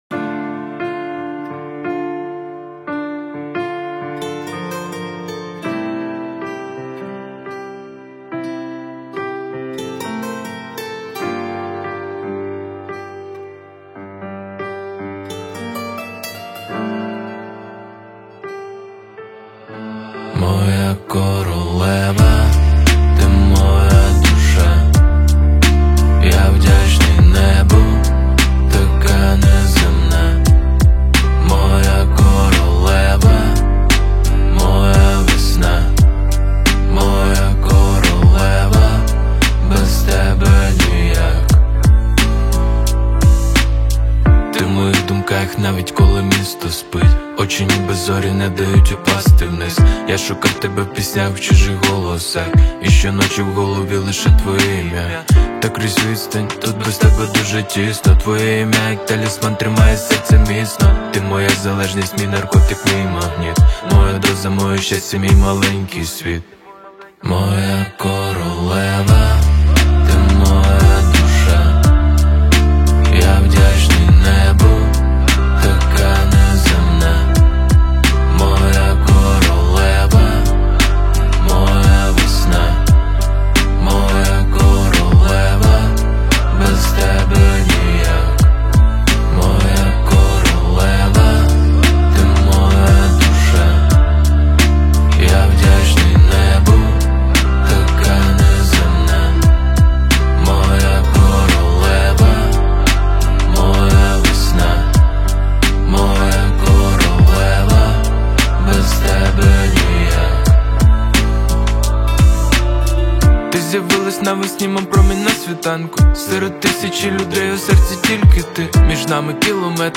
• Жанр: Реп / хіп-хоп